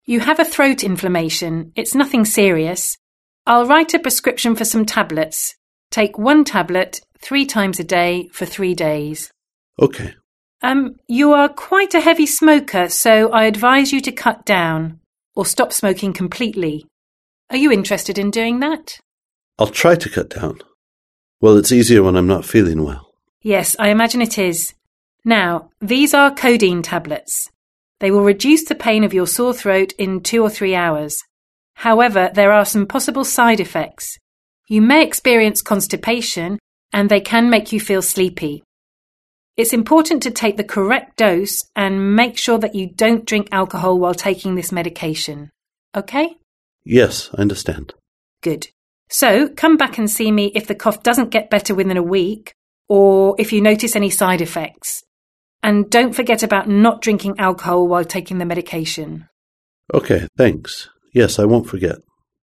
Listen to three dialogues between doctors and patients.